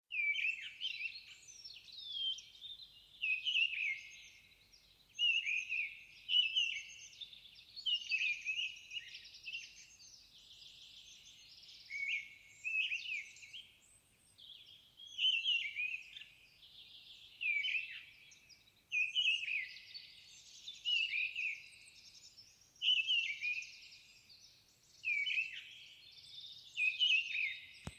Mistle Thrush, Turdus viscivorus
StatusSinging male in breeding season